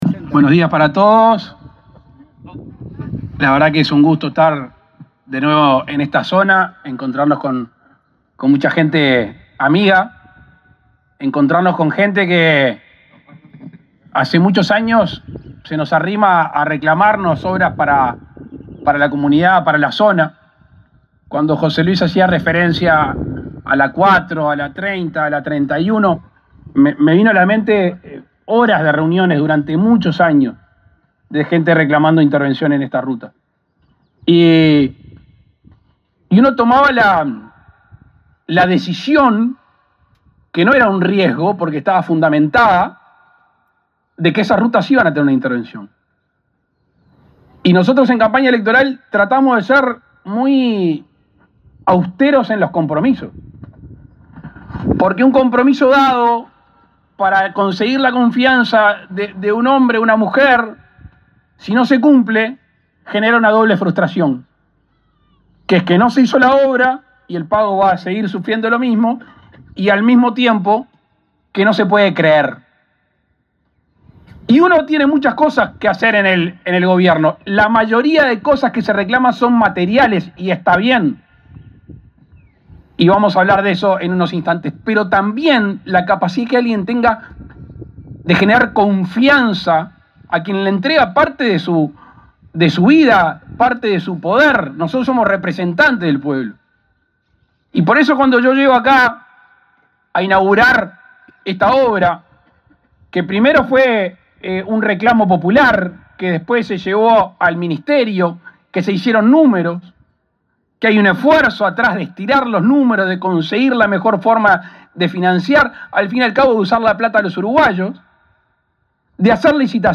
Palabras del presidente Luis Lacalle Pou
Este martes 5, el presidente de la República, Luis Lacalle Pou, encabezó el acto de inauguración de obras viales en el tramo de la ruta 3 comprendido